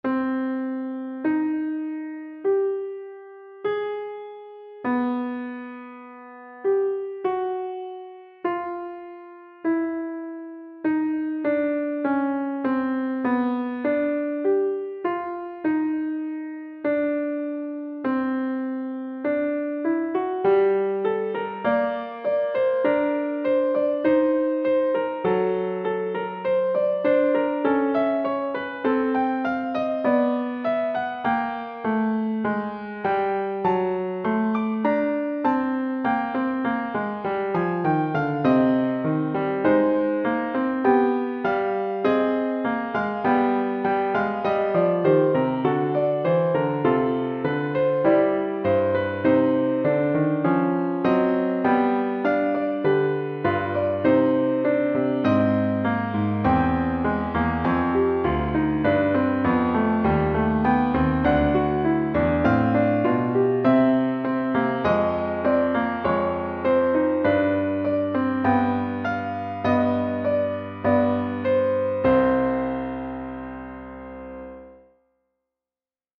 Fugue with a theme similar to the musical offering - Piano Music, Solo Keyboard - Young Composers Music Forum